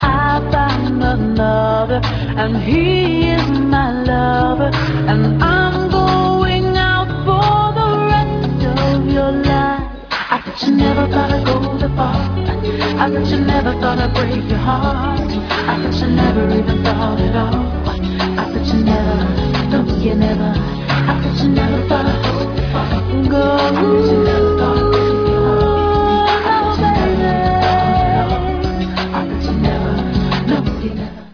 background vocals, keyboards and drum programming